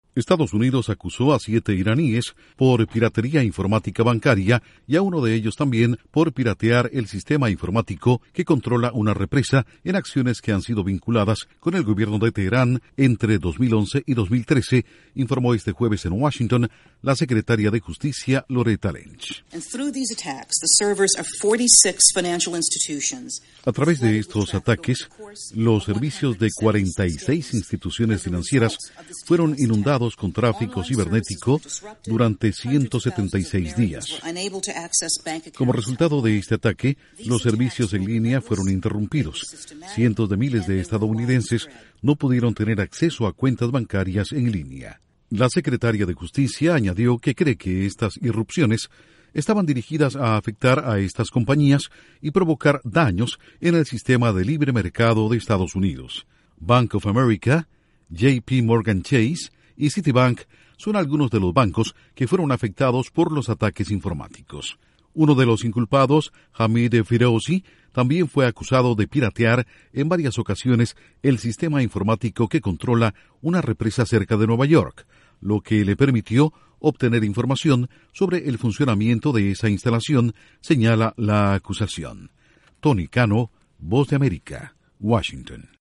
Intro: Casi cincuenta instituciones financieras han sido objeto de ataques cibernéticos dice secretaria de Justicia de Estados Unidos. Informa desde la Voz de América en Washington